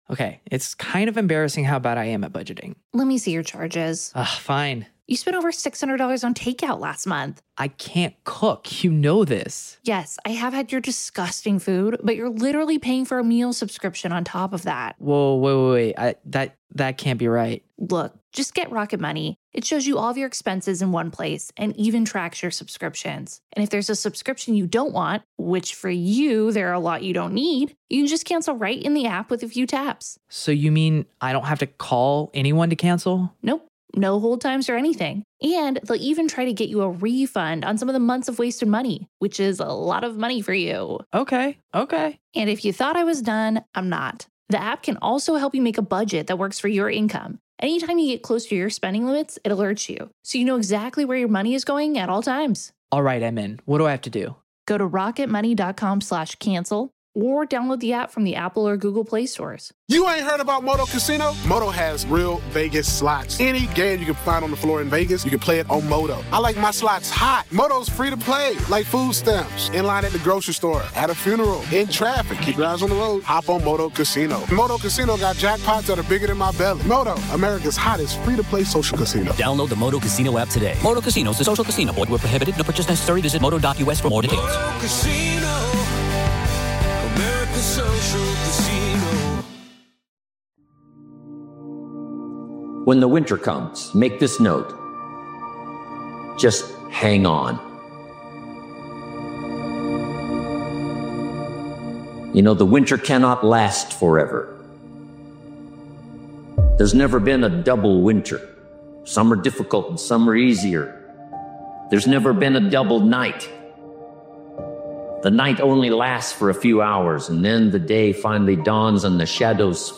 Powerful Motivational Speeches Video is a grounded and honest motivational video created and edited by Daily Motivations.